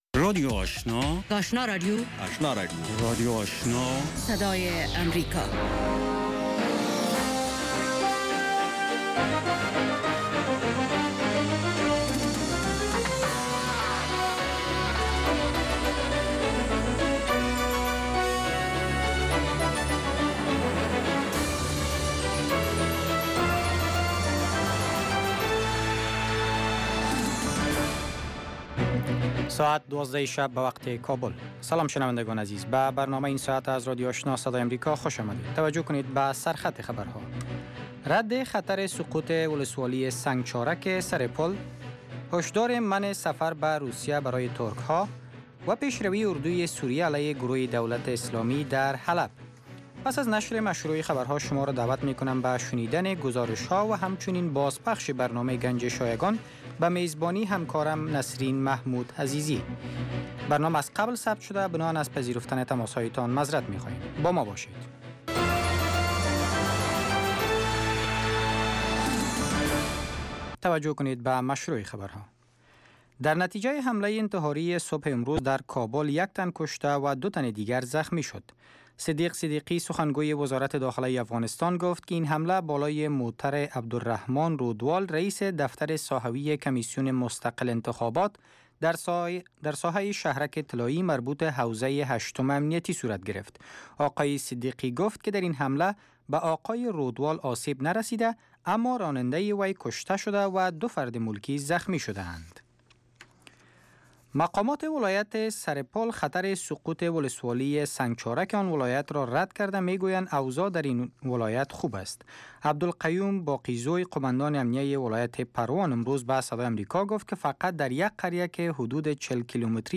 سومین برنامه خبری شب